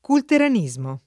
culteranismo [ kulteran &@ mo ]